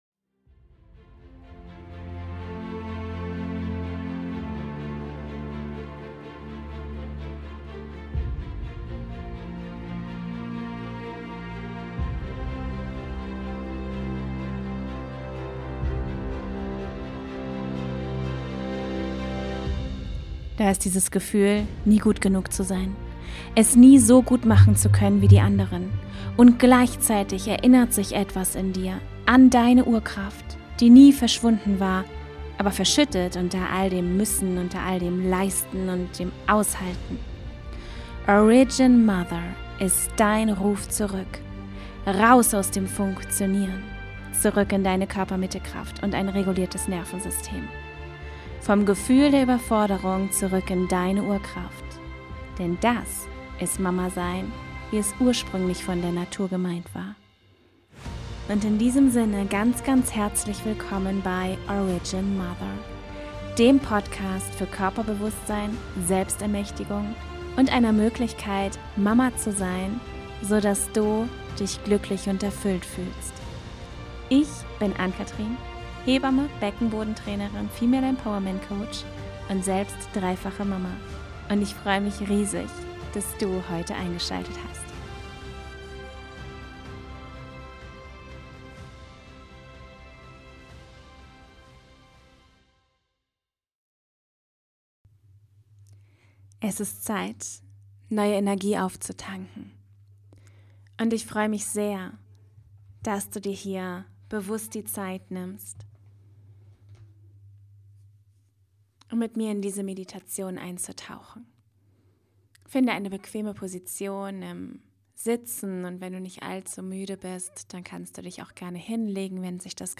Diese Meditation ist dein Ort zum Auftanken.